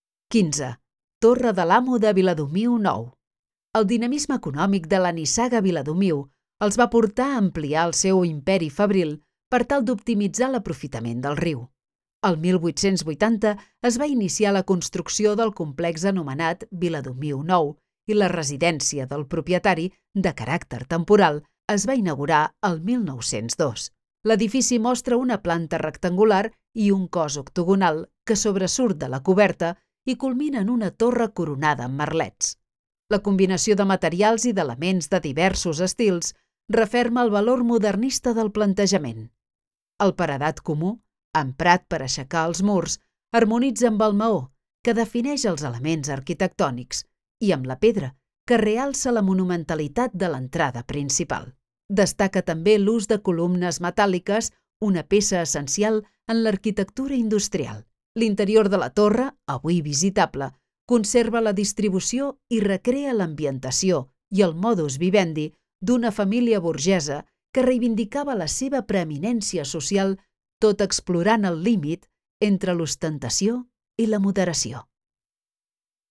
Audioguia